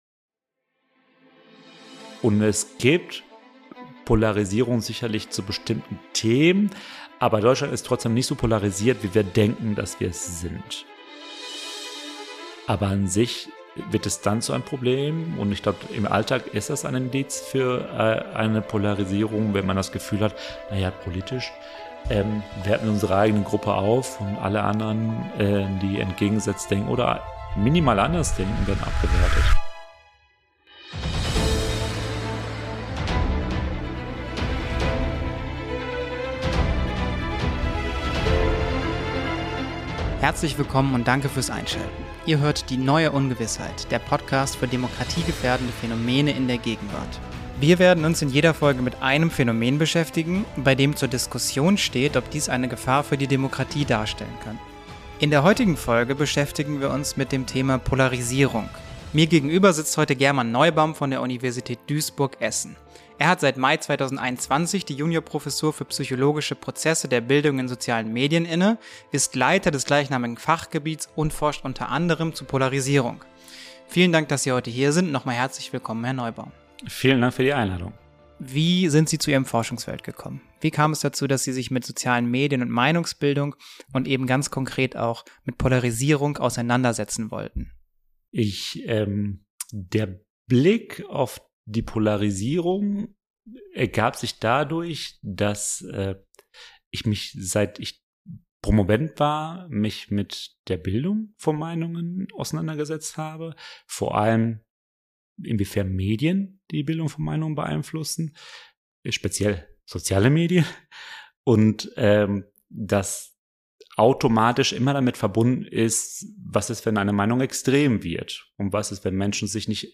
In diesem Gespräch bietet er uns einen tiefen Einblick in seinen Forschungsbereich.